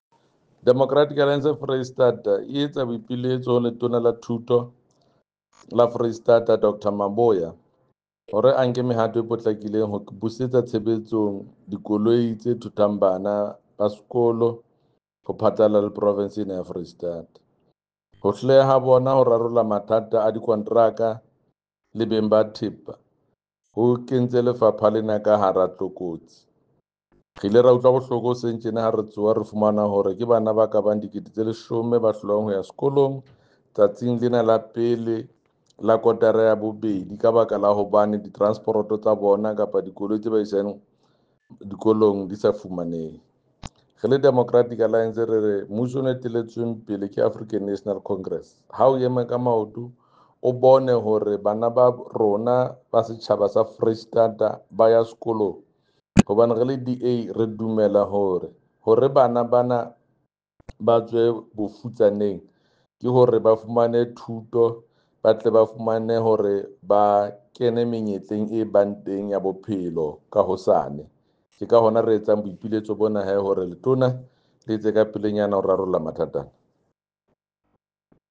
Sesotho soundbites by Jafta Mokoena MPL and